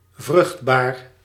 Ääntäminen
IPA : /ˈfɜːtaɪl/